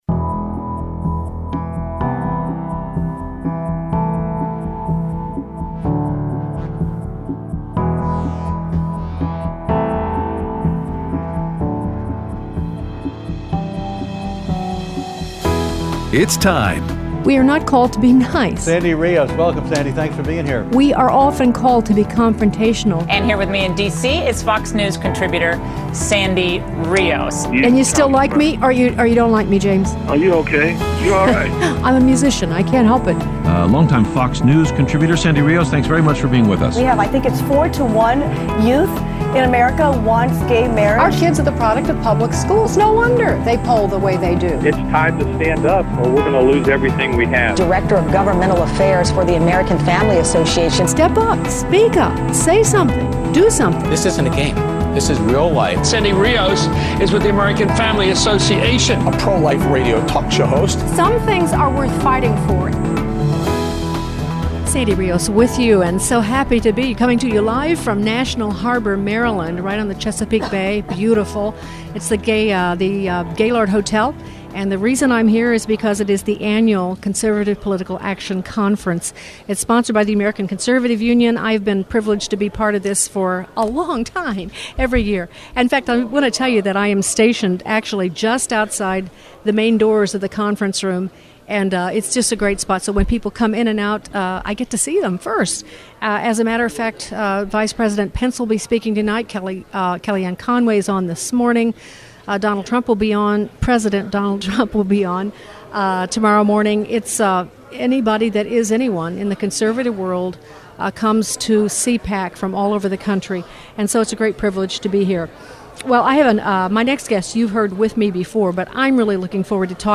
Live at CPAC with Kelli Ward